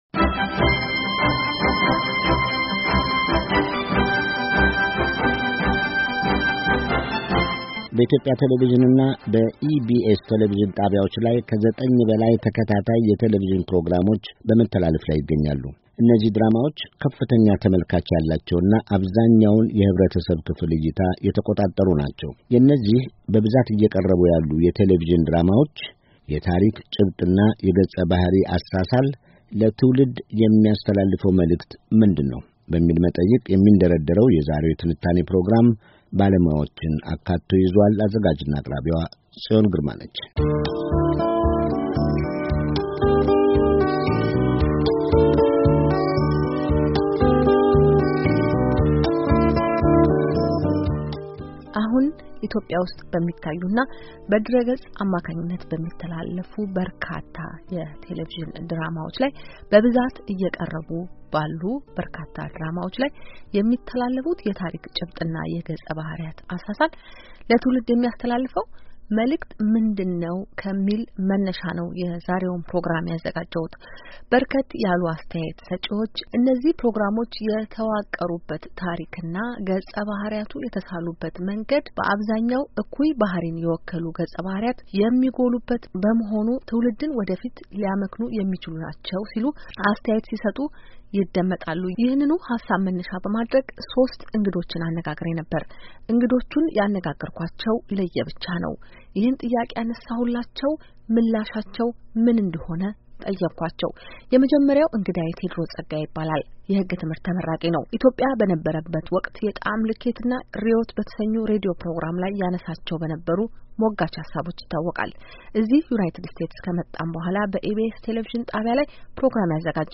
በአሁኑ ወቅት በኢትዮጵያ ቴሌቭዥን እና በኢቢኤስ ቴሌቭዠን ጣቢያዎች ላይ ከዘጠኝ በላይ ተከታታይ የቴሌቭዠን ፕሮግራሞች በመተላለፍ ላይ ይገኛሉ፡፡ ድራማዎቹ በከፍተኛ ሁኔታ አብዛኛውን የኅብረተሰብ ክፍል እይታ የተቆጣጠሩ ናቸው፡፡ በእነዚህ በብዛት እየቀርቡ ባሉ የቴሌቭዥን ድራማዎች የታሪክ ጭብጥና የገጸ ባሕሪያት አሳሳል ለትውልድ የሚተላለፈው መልዕክት ምንድነው? በሚል መጠይቅ የሚንደረደረው የዛሬው የትንታኔ ፕሮግራም ባለሞያዎችን አካቶ ይዟል፡፡